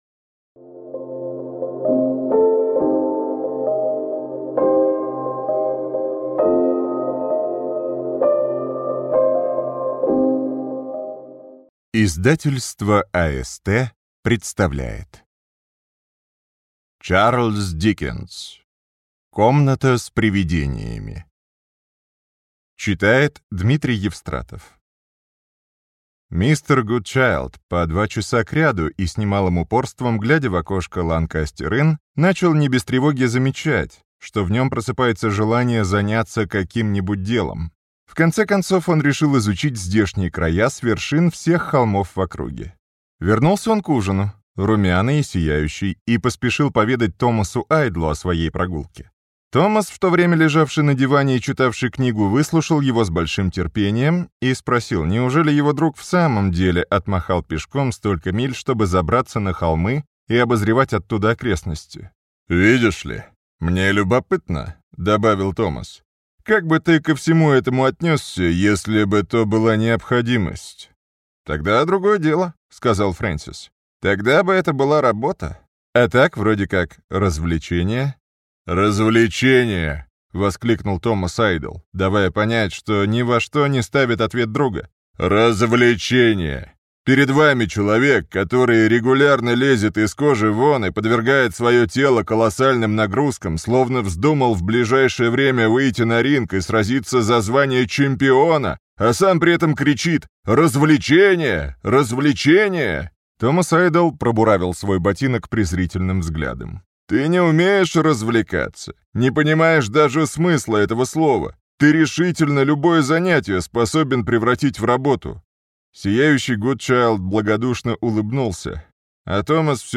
Аудиокнига Комната с привидениями | Библиотека аудиокниг
Прослушать и бесплатно скачать фрагмент аудиокниги